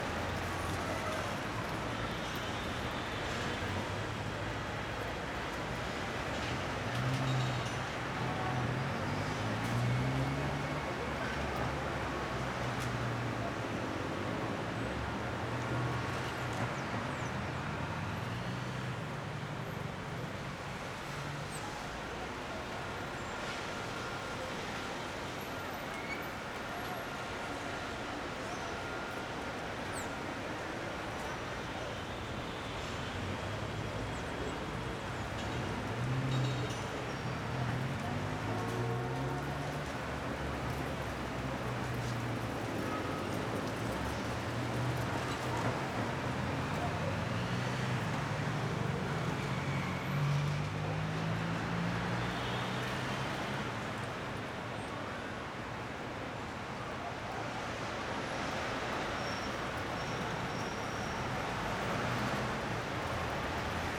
pgs/Assets/Audio/Sci-Fi Sounds/Hum and Ambience/City Loop 1.wav at master
City Loop 1.wav